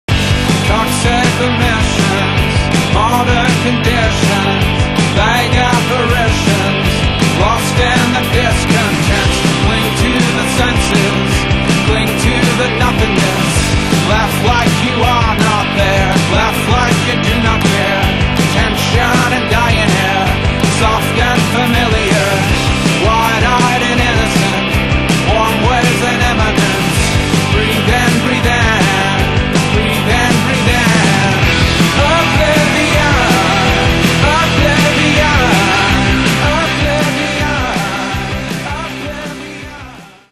guitar, vocals
drums
keyboards, backing vocals, guitar
bass guitar